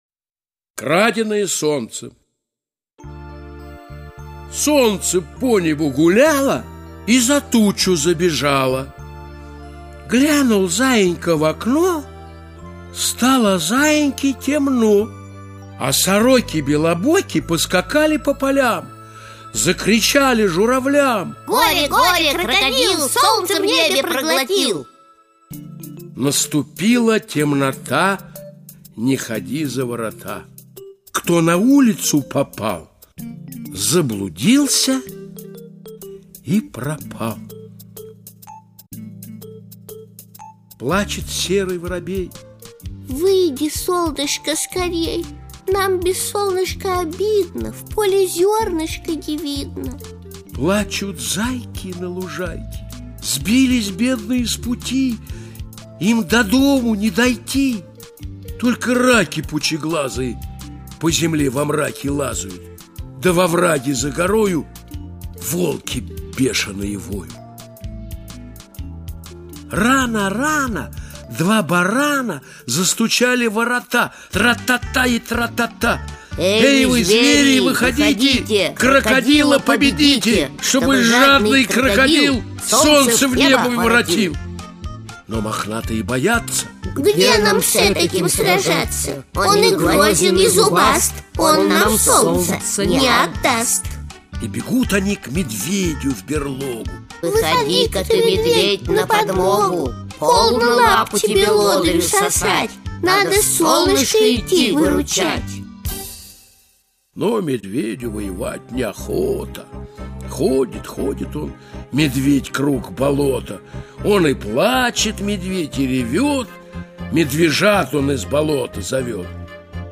• Исполнители: В. Гафт, К. Румянова